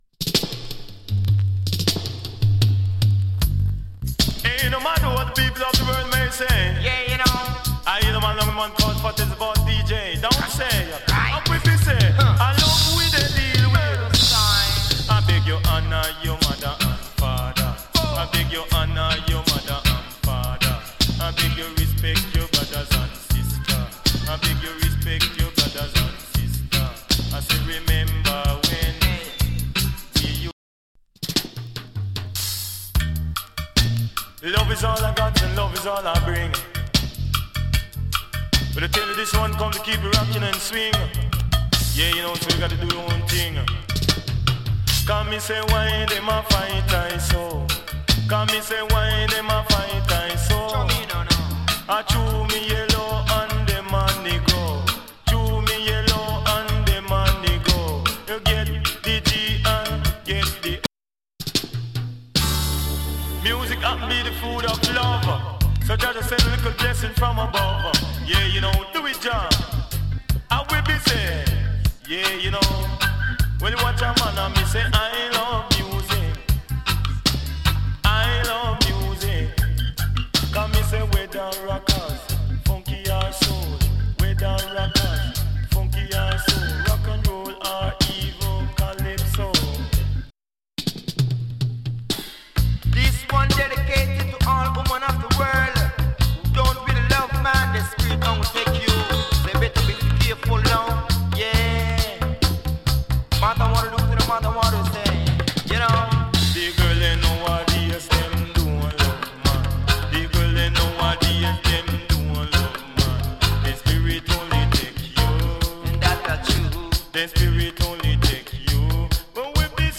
DANCE HALL ALBUM